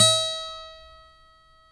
Index of /90_sSampleCDs/Roland L-CD701/GTR_Steel String/GTR_18 String
GTR 6-STR210.wav